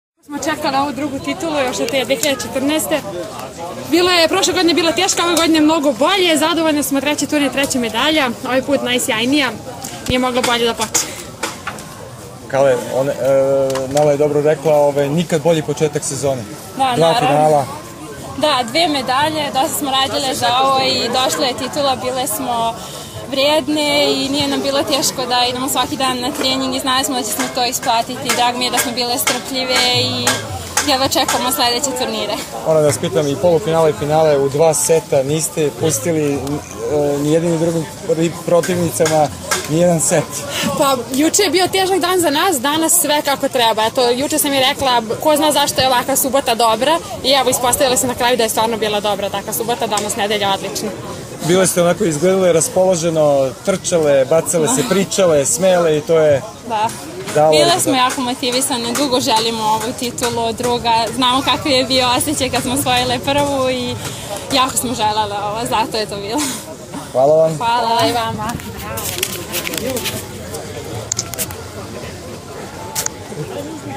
IZJAVA